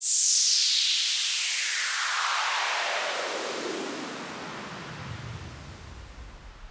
MB Trans FX (17).wav